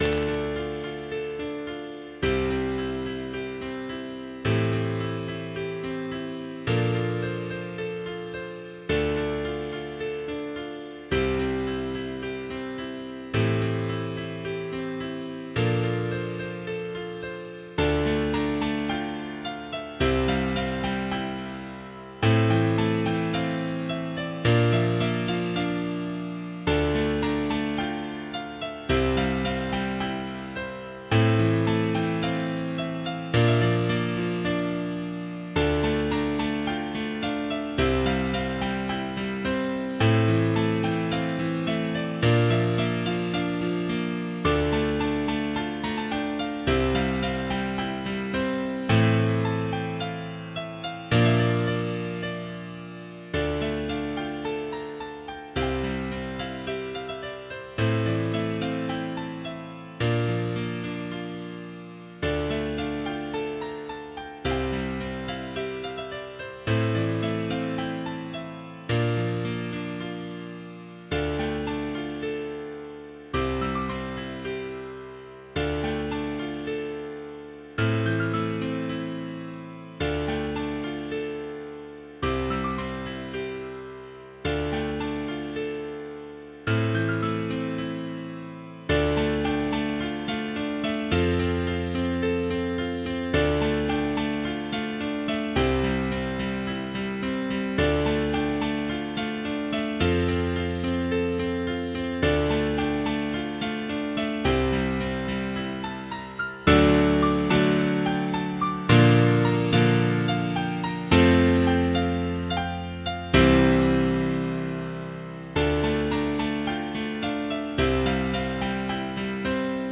MIDI 11.34 KB MP3